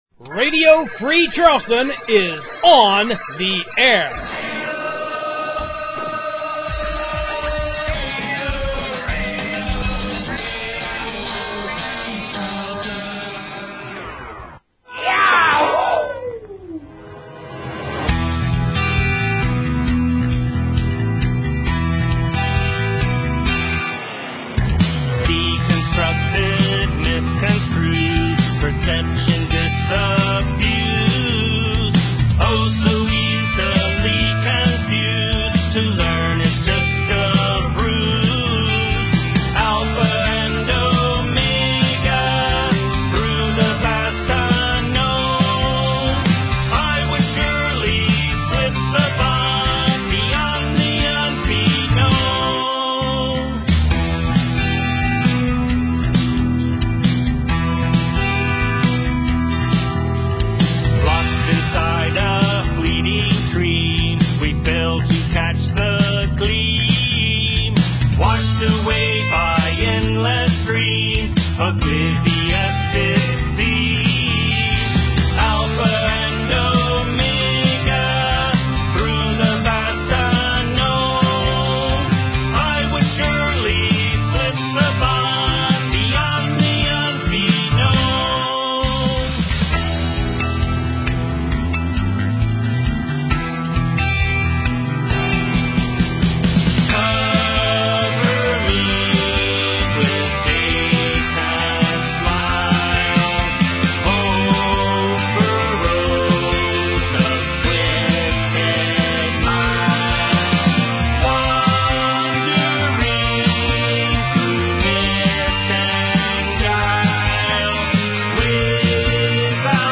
Radio Free Charleston brings you a new show with THREE FULL HOURS of newly-assembled free-format radio.
It’s all the primo-big meaty musical goodies for the entire show.